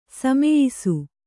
♪ sameyisu